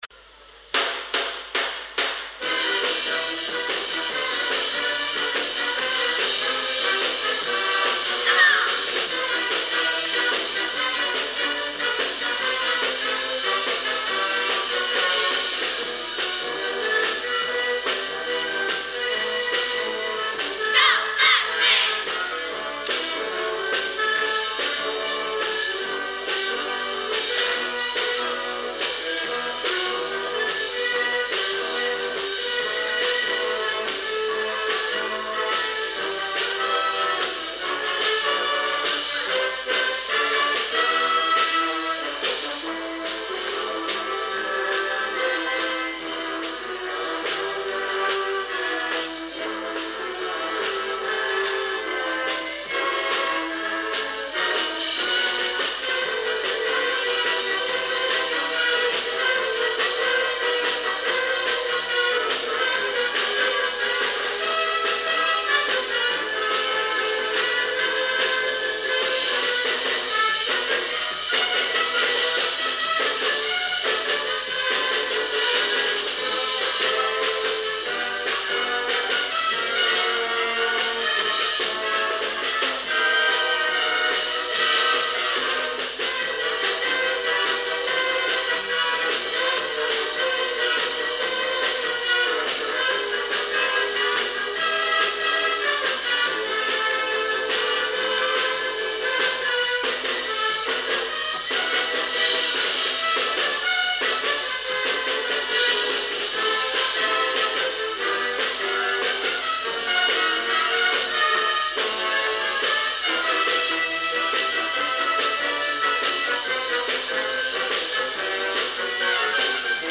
合奏を録音したMP3ファイルです。
2000年春の慶早戦チア曲。
録音は2000年の富岡六旗です。
演奏はこの年の当番校、明治大学です。